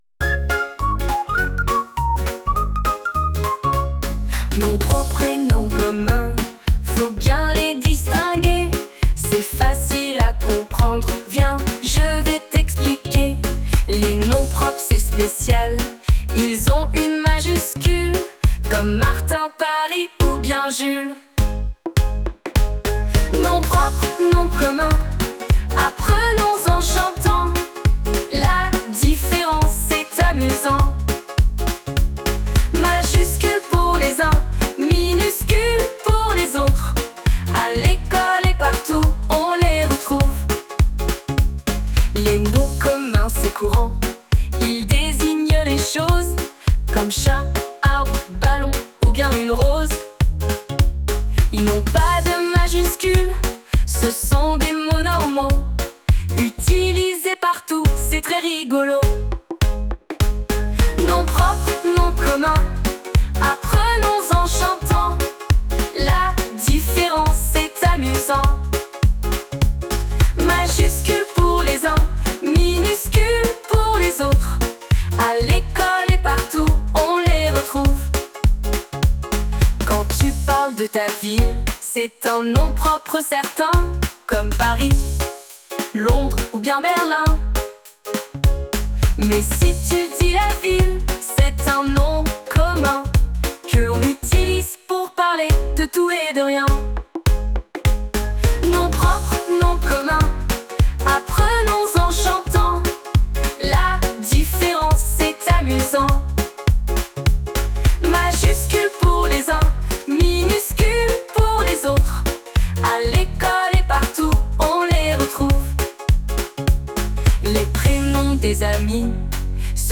Des chansons pour apprendre ! Et pour le plaisir !